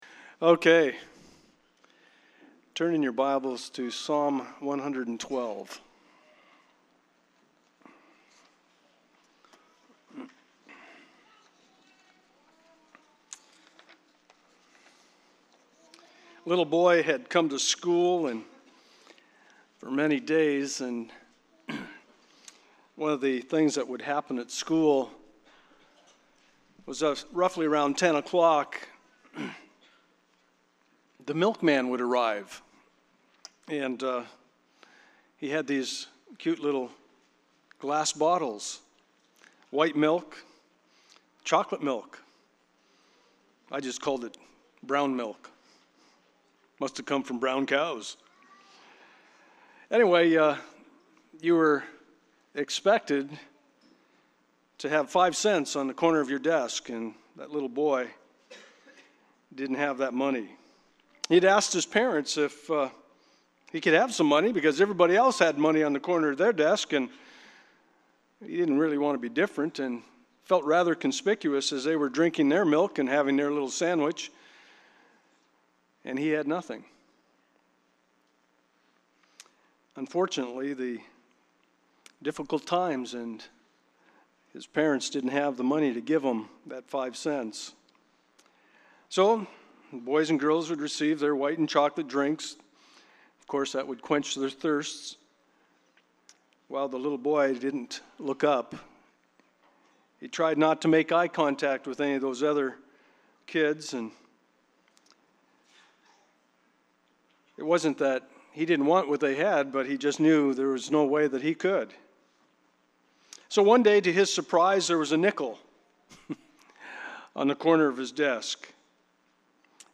Passage: 2 Corinthians 9:1-15 Service Type: Sunday Morning